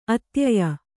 ♪ atyaya